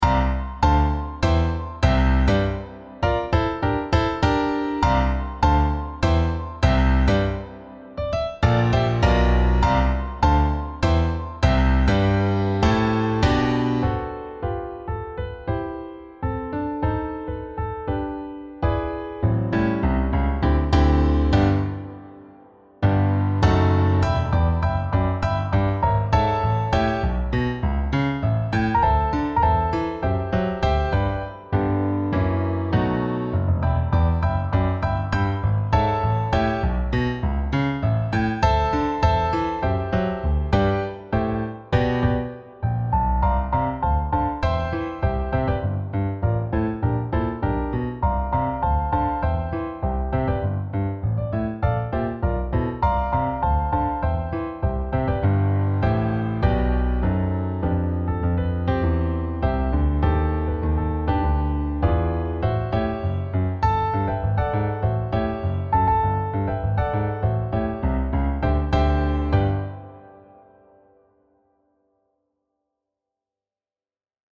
Instrumentierung: Klavier solo